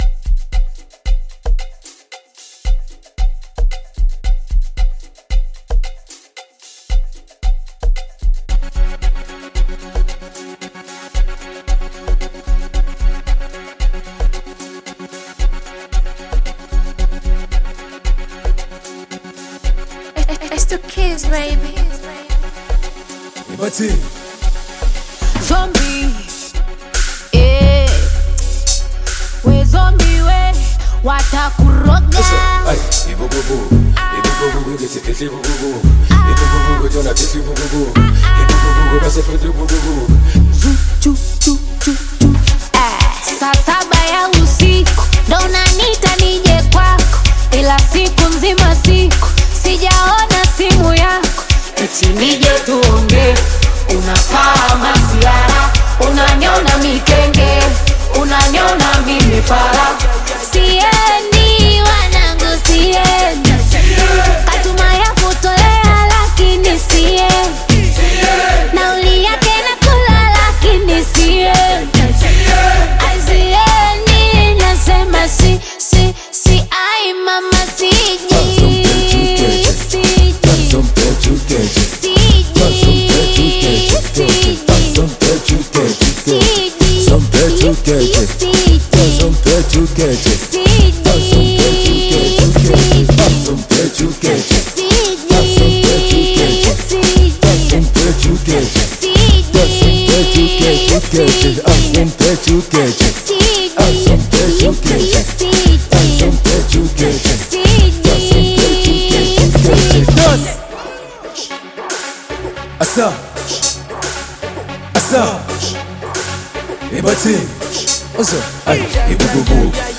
Bongo Flava music track
Bongo Amapiano song